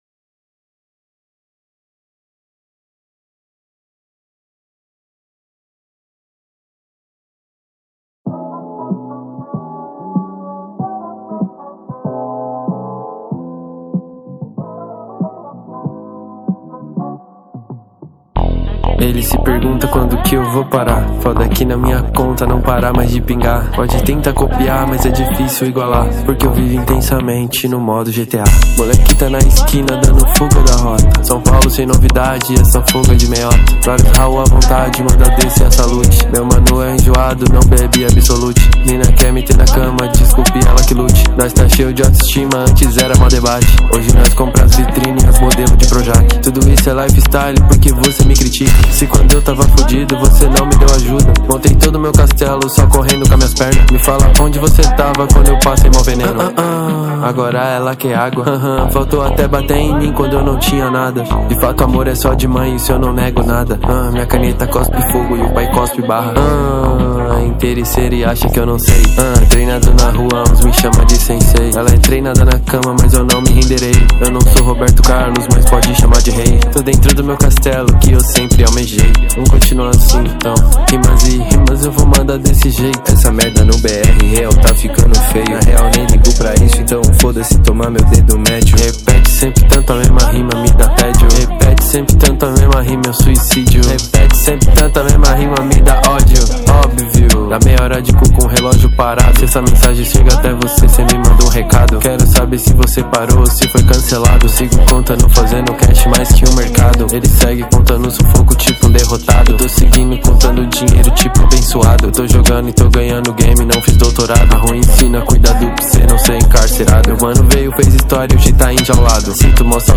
2024-09-25 22:27:34 Gênero: Trap Views